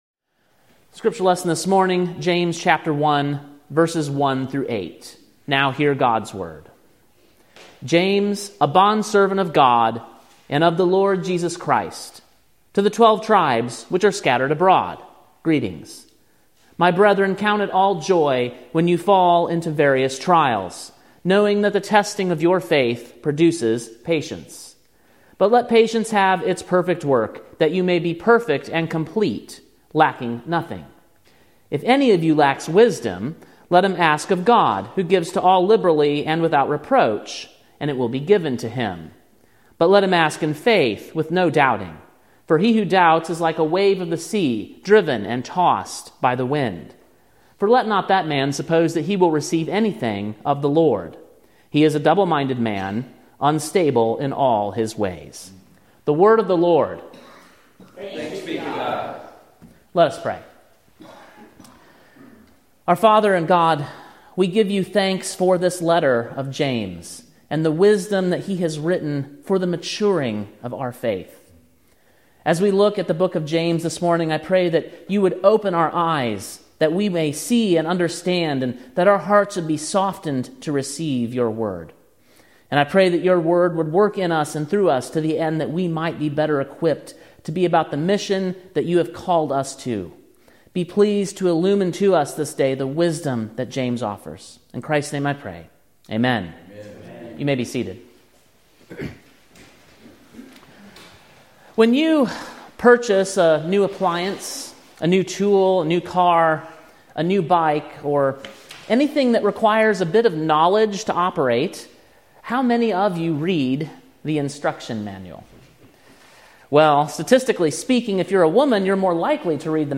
Sermon preached on April 14, 2024, at King’s Cross Reformed, Columbia, TN.